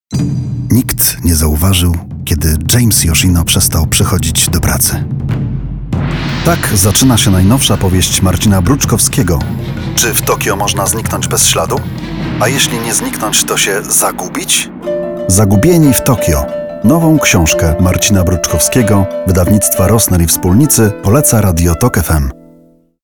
Male 30-50 lat
Zapowiedź telefoniczna